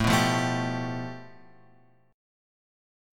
A7sus2#5 chord {5 2 3 2 x 3} chord